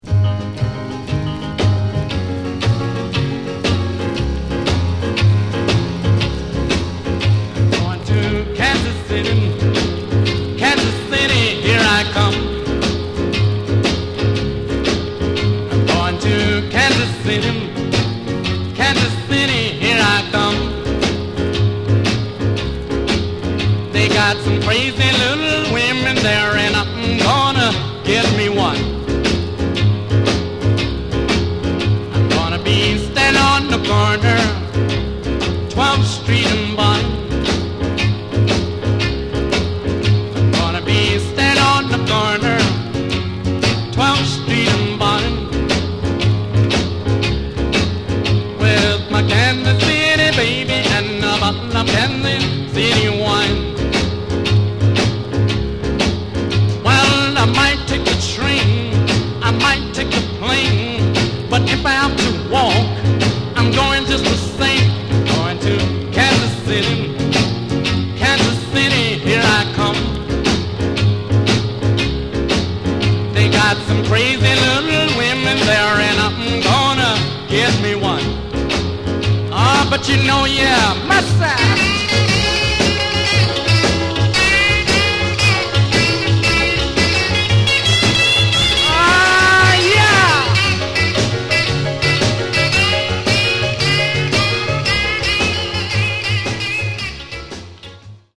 Genre: Rockin' RnB